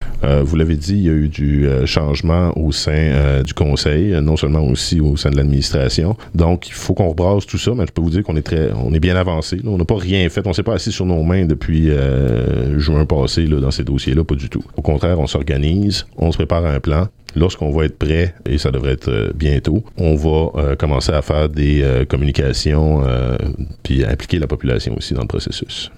En entrevue à l’émission Cap info, mercredi midi, le maire des Îles et président de la Communauté maritime, Antonin Valiquette, maintient qu’il sera important de consulter les citoyens dans ce dossier. Il affirme toutefois que le conseil municipal n’a pas encore toutes les informations nécessaires pour organiser une rencontre publique, en cas d’annulation de la mesure ou de maintien de la formule volontaire.